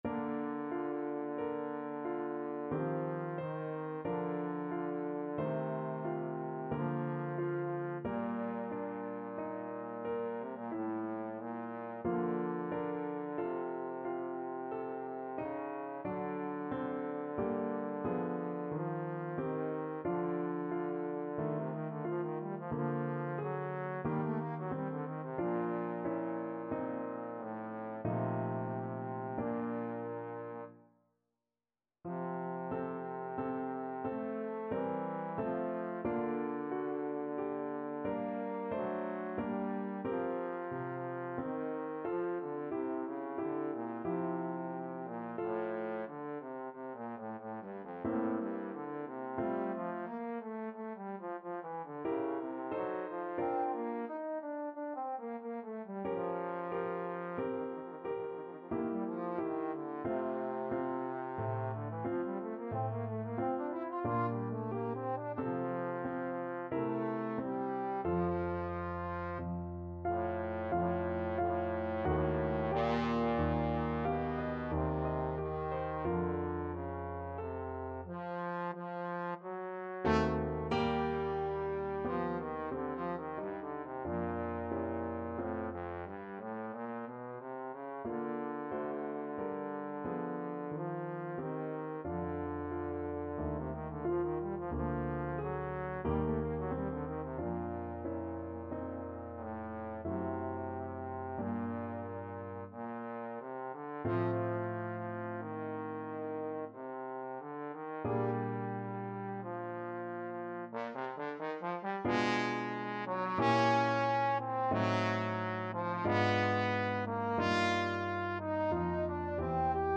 Trombone version
3/4 (View more 3/4 Music)
Adagio =45
Trombone  (View more Intermediate Trombone Music)
Classical (View more Classical Trombone Music)